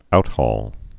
(outhôl)